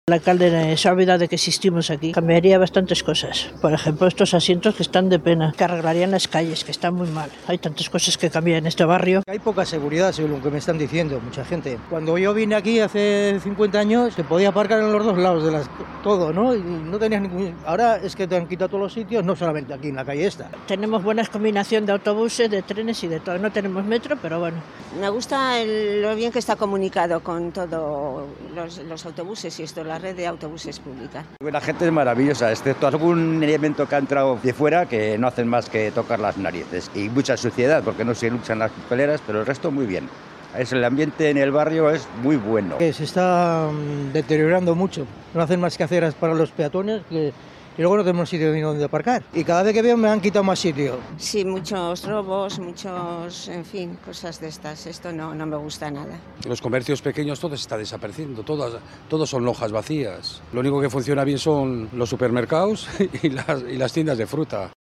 En 'Bilbao al habla' conocemos la opinión de los vecinos de Zorroza
Las vecinas y vecinos han compartido sus impresiones sobre la situación actual del barrio, destacando tanto sus virtudes como sus carencias.